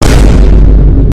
VINE BOOM LOUD Sound Button
Vine boom loud sound button is a short, punchy audio clip that people love using in memes, gaming streams, and reaction edits.